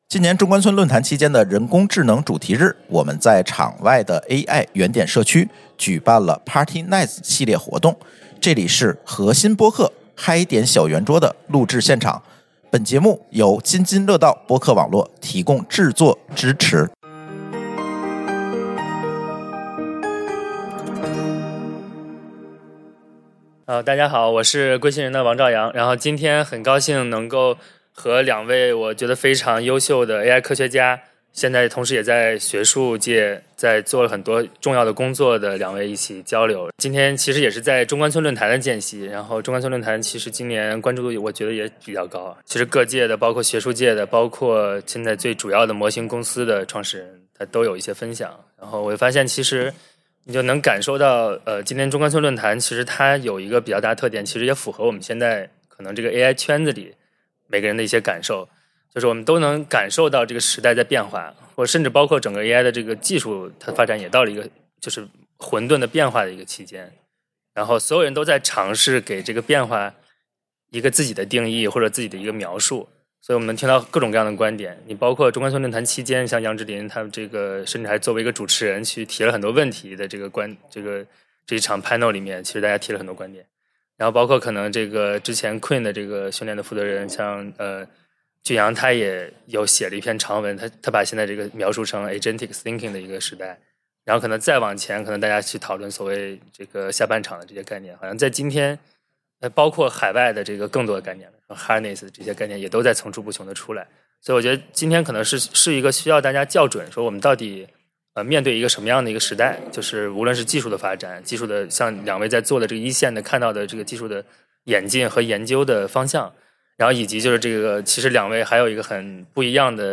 我们避开关于算力和估值的宏大叙事，在 AI 原点社区的小圆桌旁，和两位刚刚从硅谷大厂“回归”实验室的科学家聊了聊智能的底色。